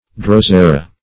Drosera \Dros"e*ra\ (dr[o^]s"[-e]*r[.a]), n. [NL., fr. Gr.